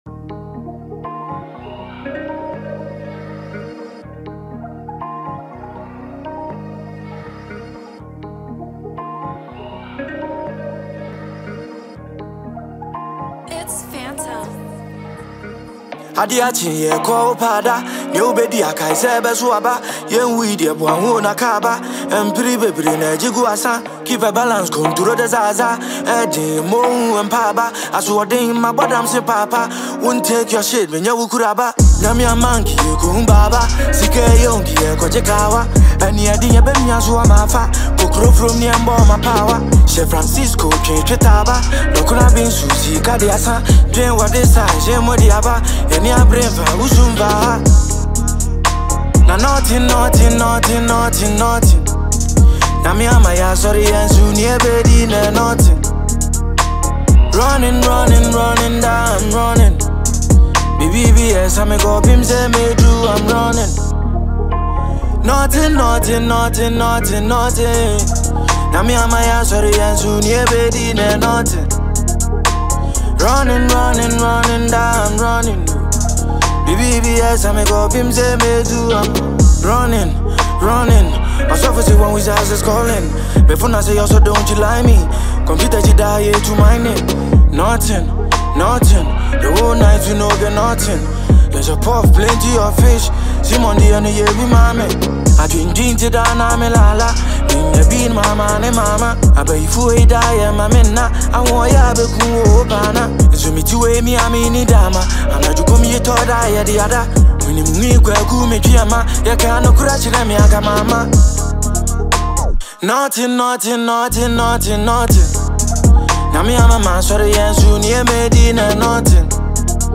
Known for his deep storytelling and raw delivery
hip-hop song
and a strong beat